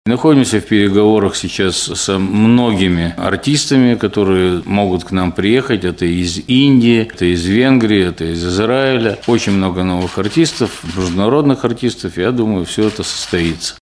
на пресс-конференции «ТАСС-Урал»